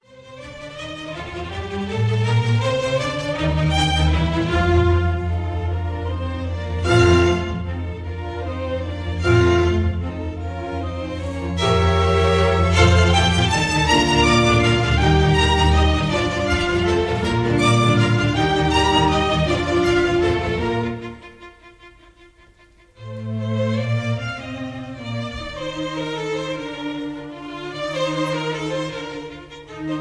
Recorderd live at the 1st Aldeburgh